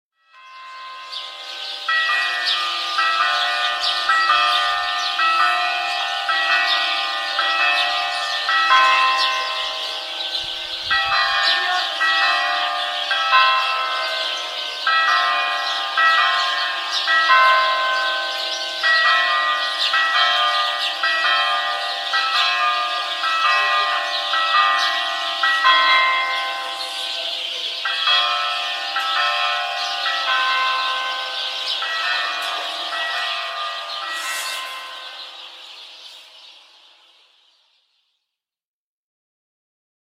دانلود صدای ناقوس کلیسا 2 از ساعد نیوز با لینک مستقیم و کیفیت بالا
جلوه های صوتی